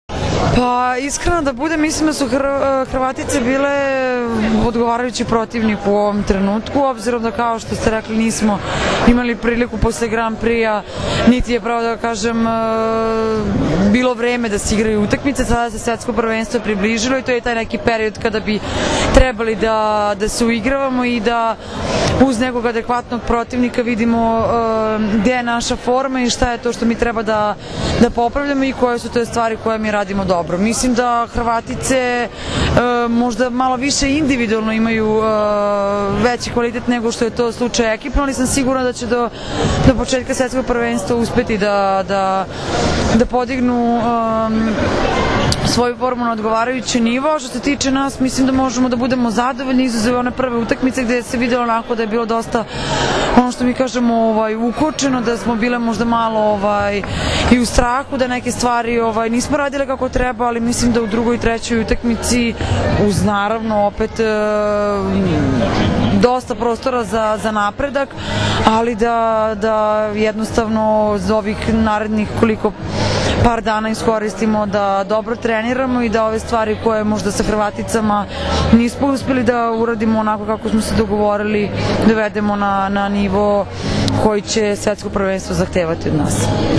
IZJAVA MAJE OGNJENOVIĆ